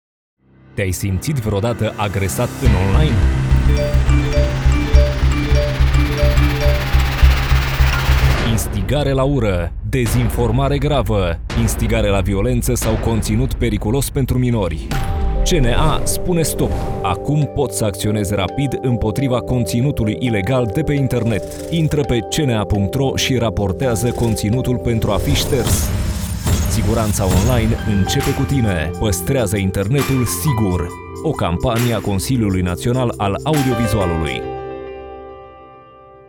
Spot radio Campanie CNA